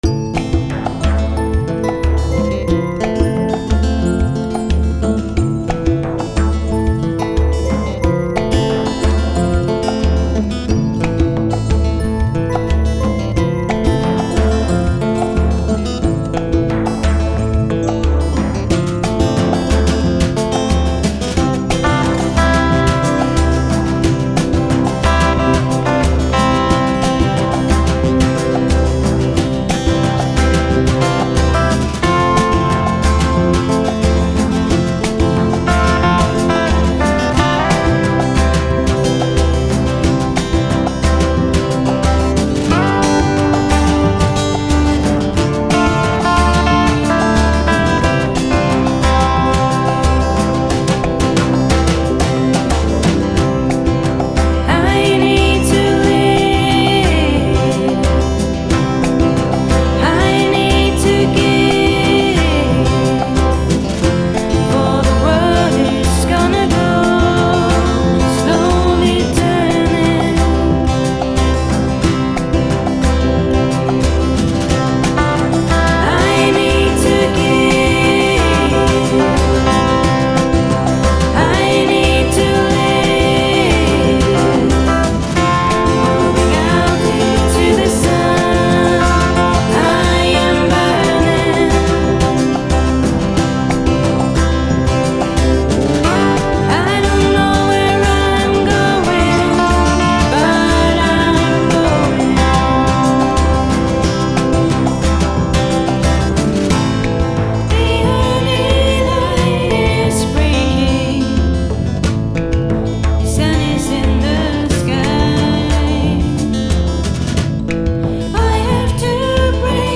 Vocals
Gitarre